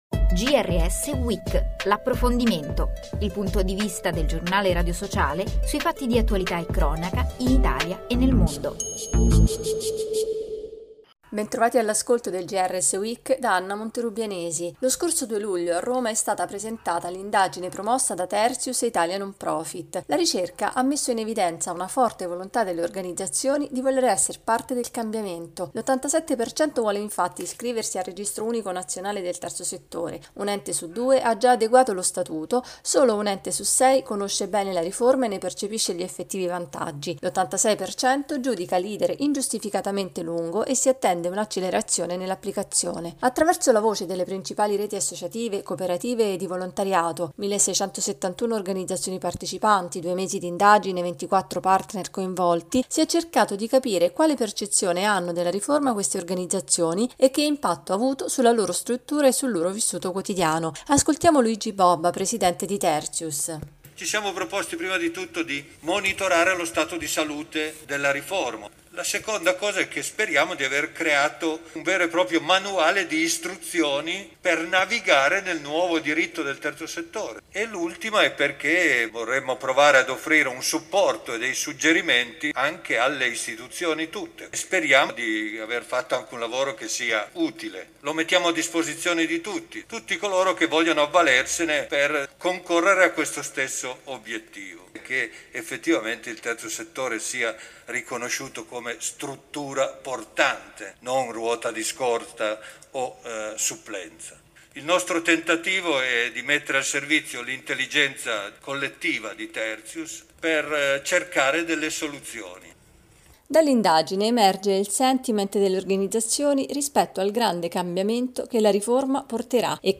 Ascoltiamo Luigi Bobba, Presidente di Terzjus